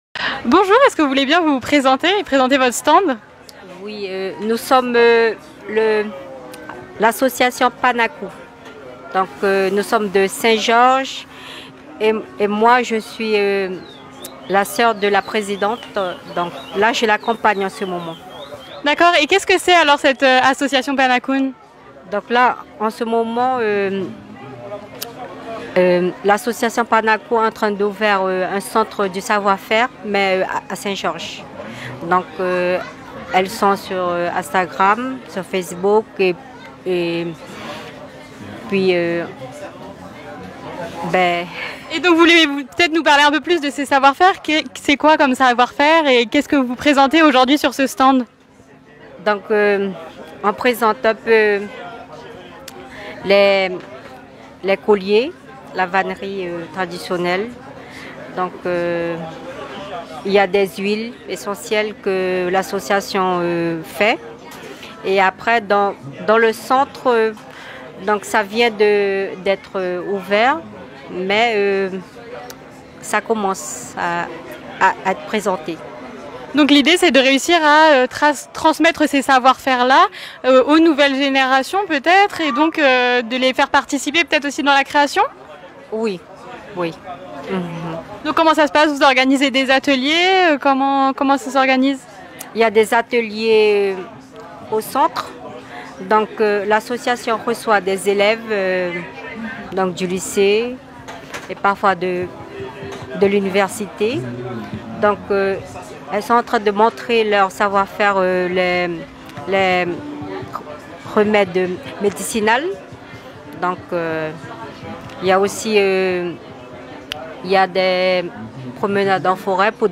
Nous l’avons Rencontrée dans le cadre du festival ALTERNAYANA qui s’était déroulé du 3,4 et 5 juin au jardin botanique à cayenne.